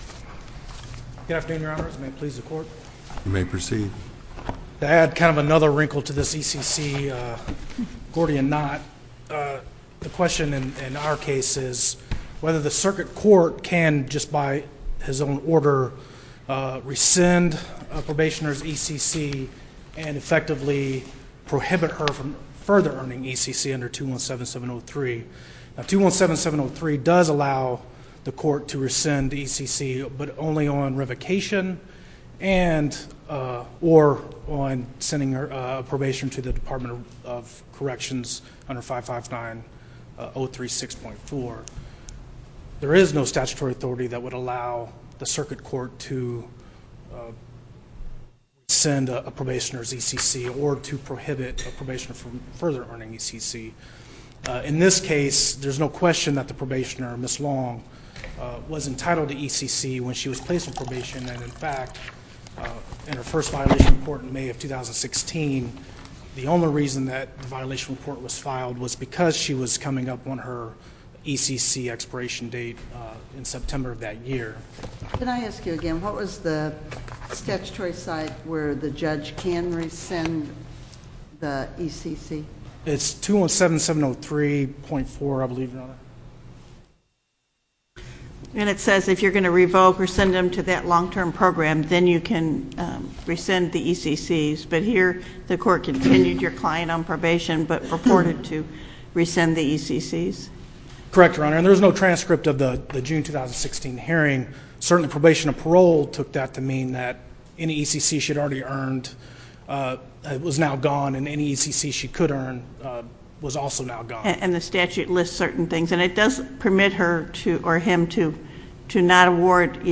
link to MP3 audio file of oral arguments in SC97198